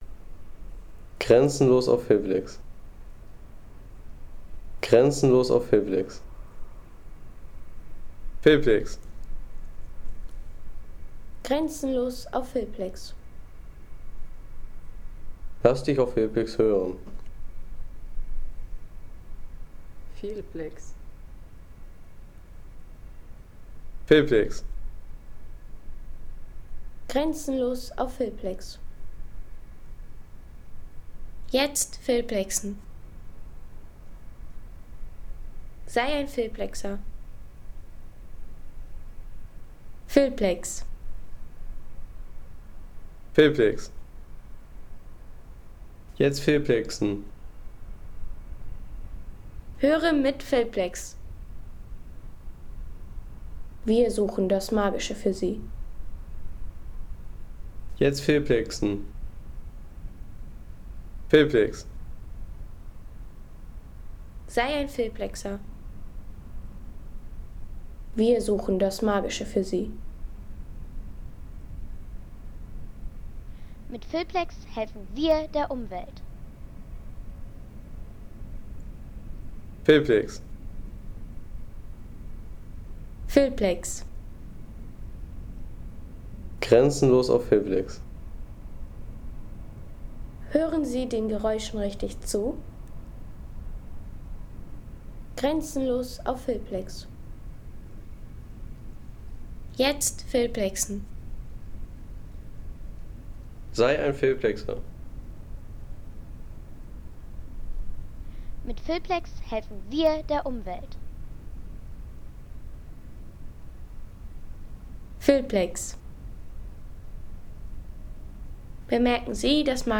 Eulenberg-Wald am Morgen: Vogelstimmen und ein Rätselruf
Herbstlicher Eulenberg-Wald in Oberfranken: Morgenstille, Vogel-Erwachen, ferne Landwirtschaftsgeräusche und ein mysteriöser Tier-Ruf.
Oberfranken im Herbst: tiefe Waldstille, erste Vogelstimmen im Licht der aufgehenden Sonne und ein unerwarteter Ruf eines unbekannten Tieres.